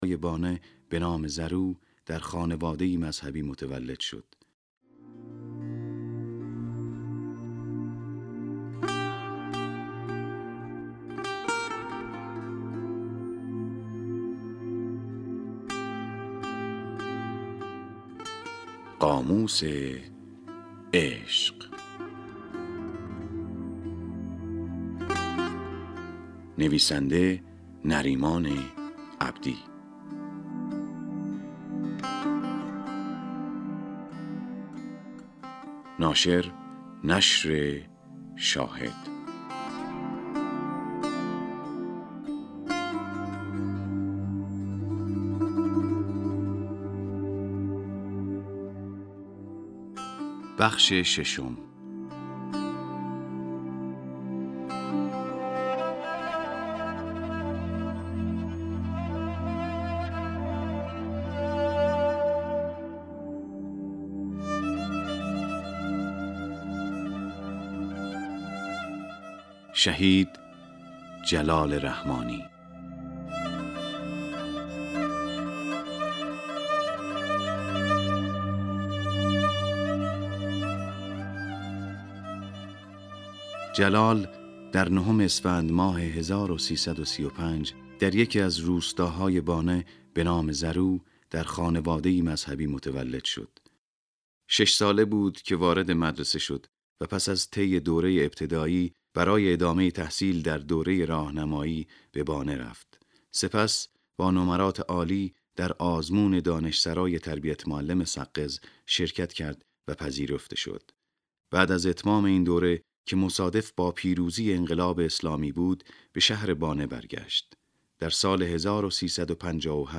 نسخه گویای «قاموس عشق» منتشر شد